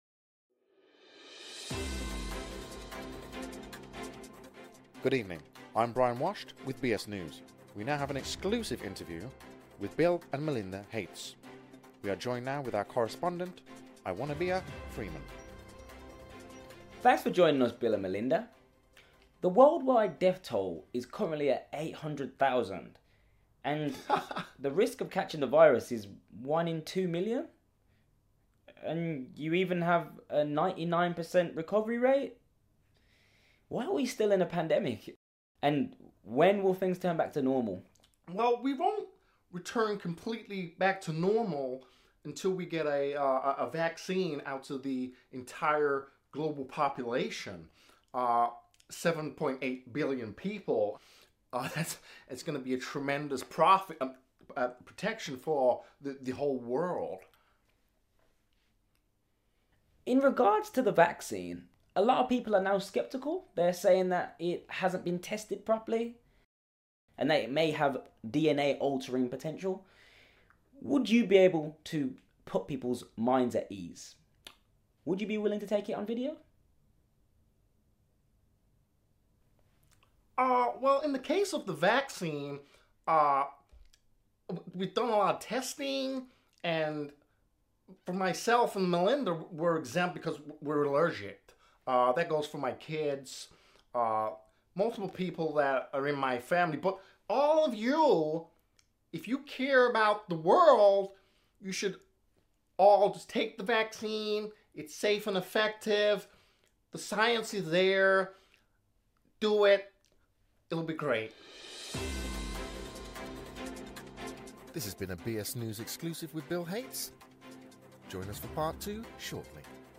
Parody of Bill Gates Interviews | Worldwide exclusive!